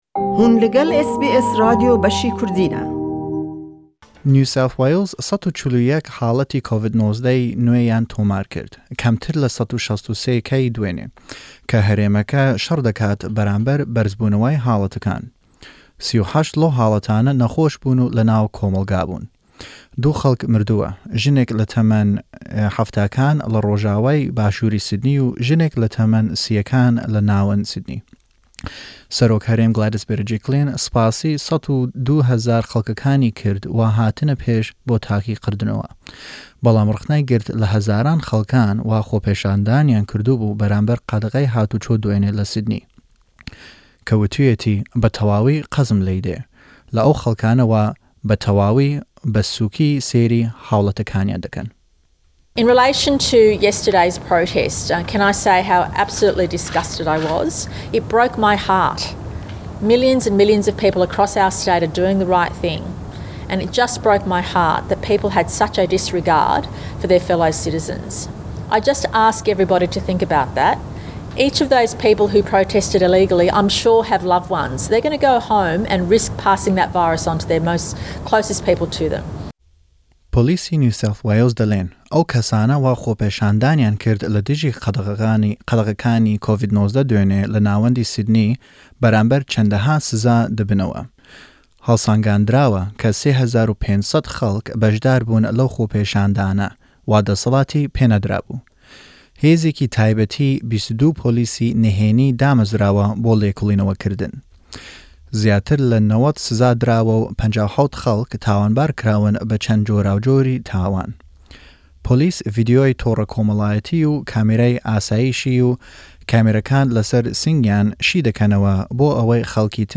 SBS Kurdish news for weekend of 24-25 July 2021.